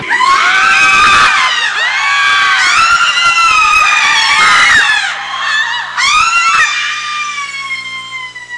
Screaming People Sound Effect
Download a high-quality screaming people sound effect.
screaming-people.mp3